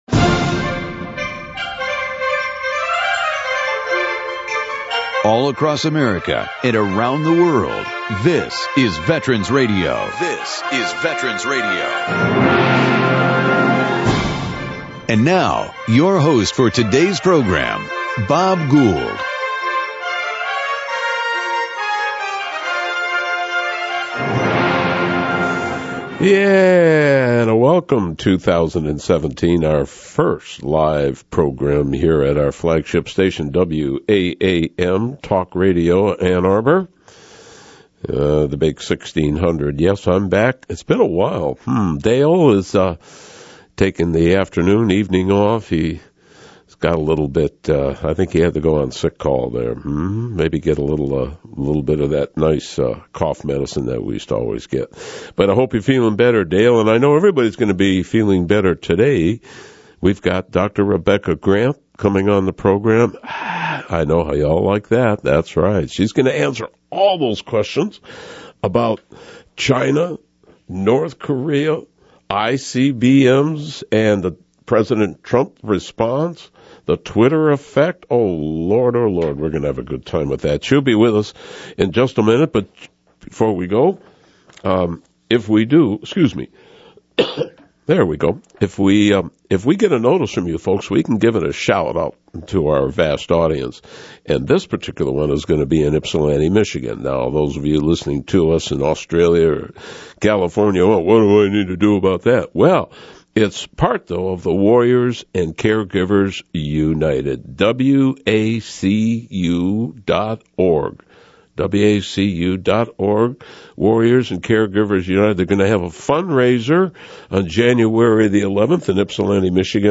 Be in the know listen to WAAM Talk Radio 1600 and Veterans Radio.